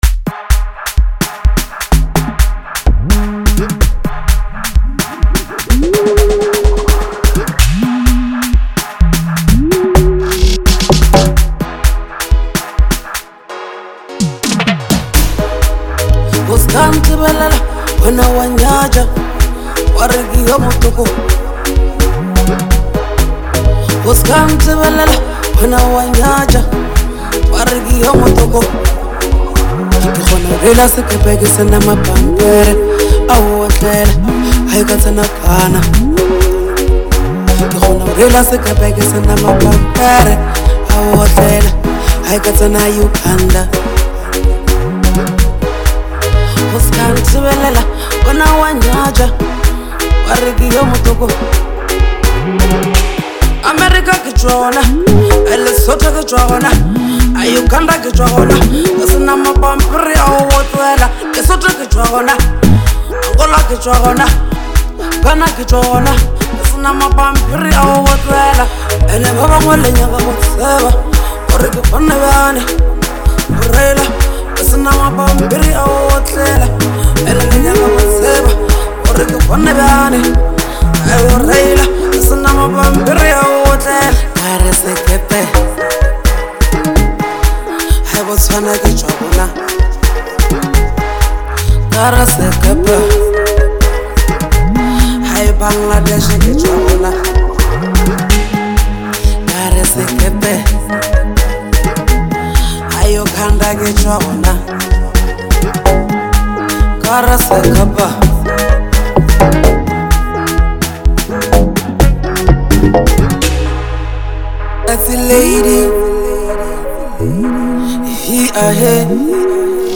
Bolo HouseLekompo
soulful vocals